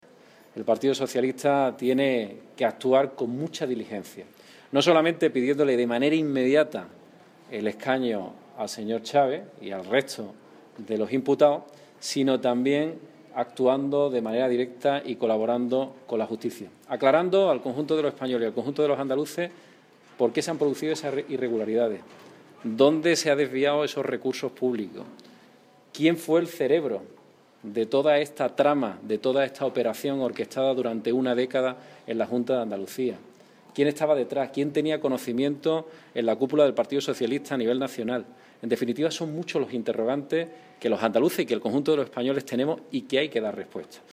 Escuche las declaraciones: